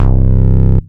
NINTYBASS C2.wav